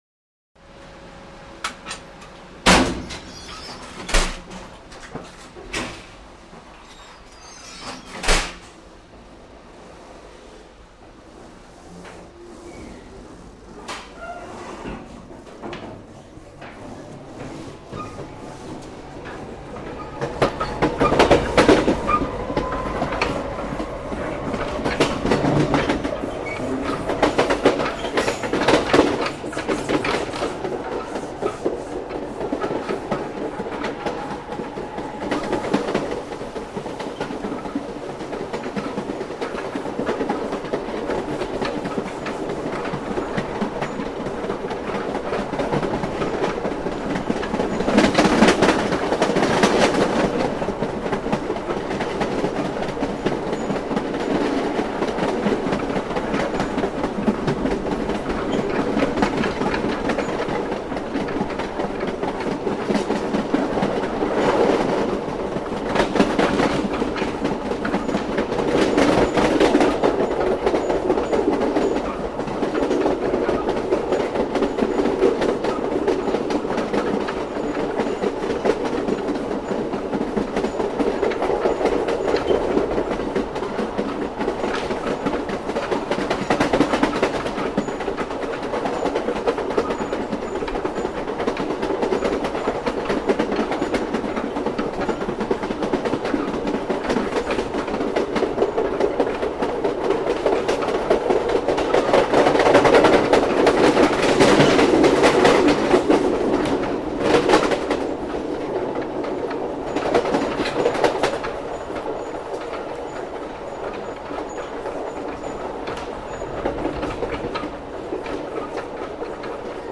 Звук отправки поезда с вокзала (запись изнутри) (03:02)
запись изнутри